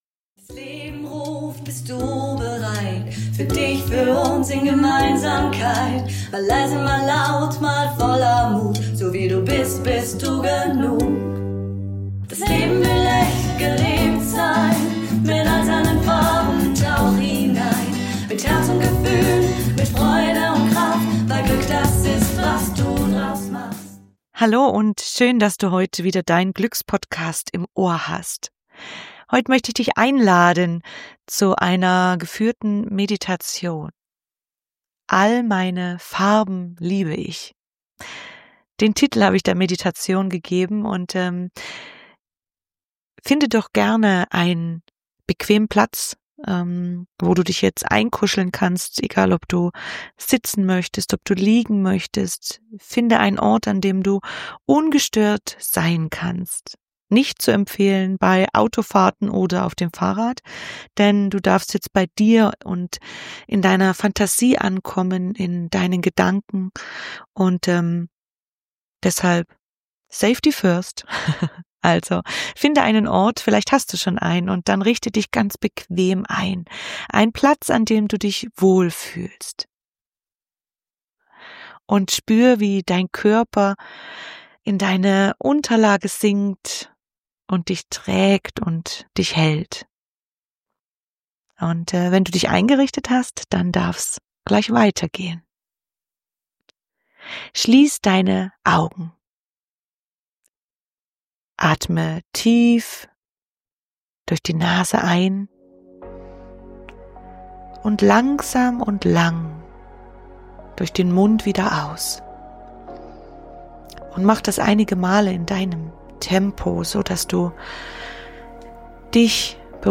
Diese geführte Meditation lädt dich ein, dein inneres Farbspektrum zu entdecken und voller Selbstliebe zu umarmen.